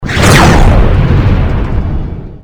youHit5.wav